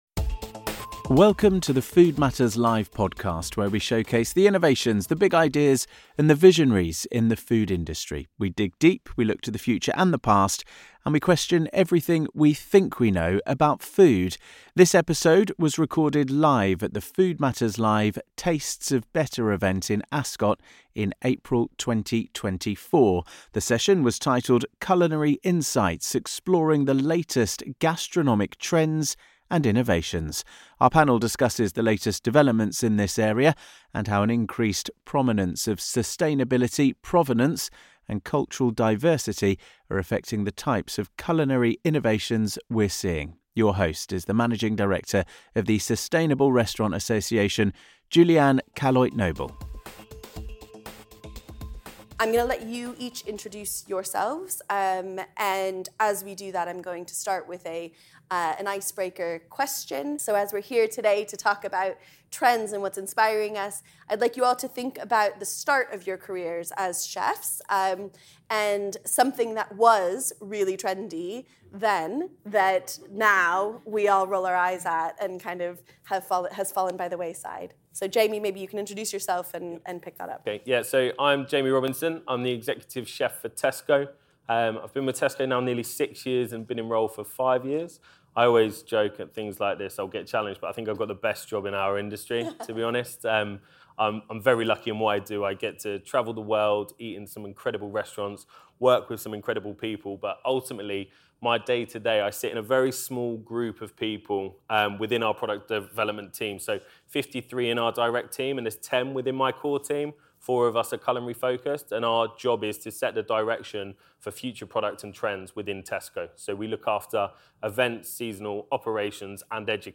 In this episode, recorded live at the Food Matters Live Tastes of Better event in Ascot in April 2024, we ask the experts where we might be heading, and which factors may be taking us there. How will an increased interest in sustainability, provenance, and cultural diversity impact our culinary experiences if the future?